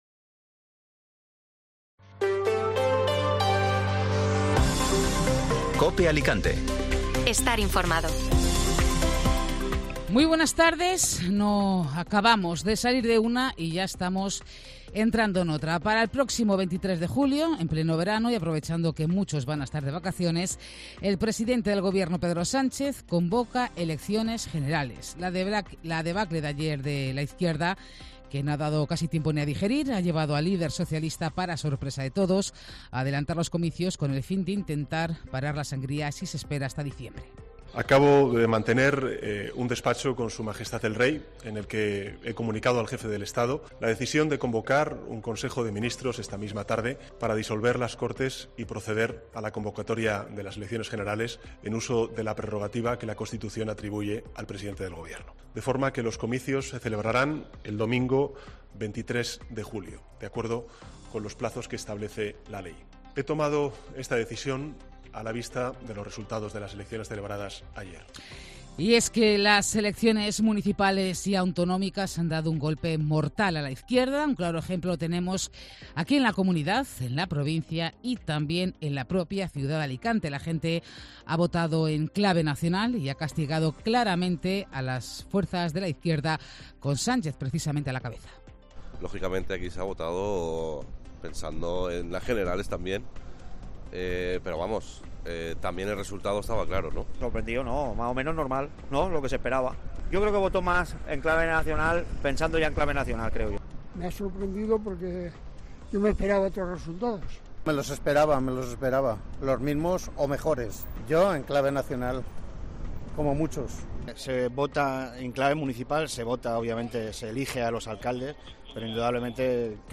Informativo Mediodía Cope Alicante (Lunes 29 de mayo)